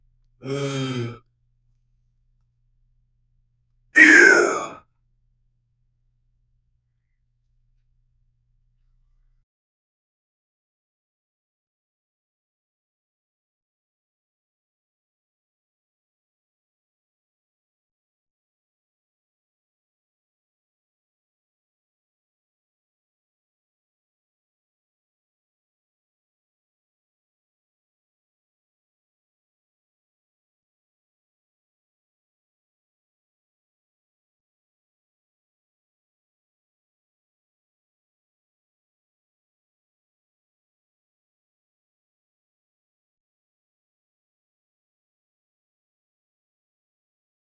Royalty-free fall sound effects
old man falls down a flight of stairs and yells
man-falling-sound--z7zjmfz4.wav